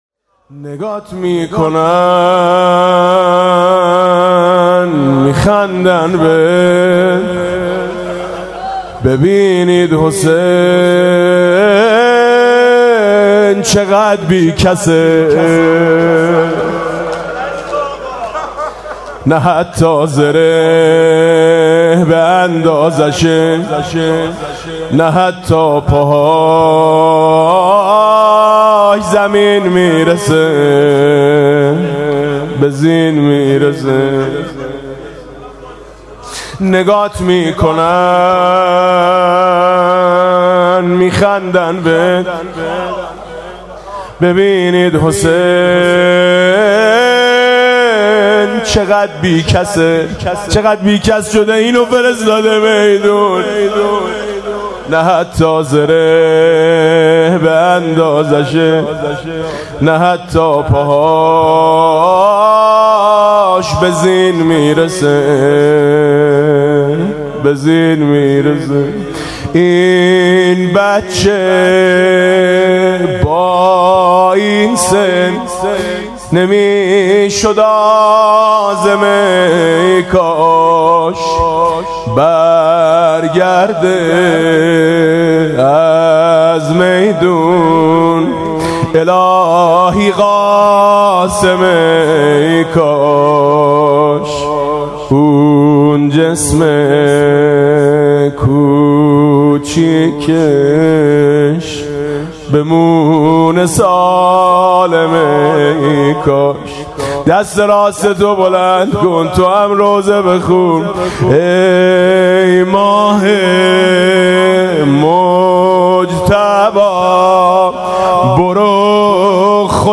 مراسم عزاداری شب ششم محرم ۱۴۰۳ با صدای میثم مطیعی
چرا دورتو همه گرفتن (زمزمه)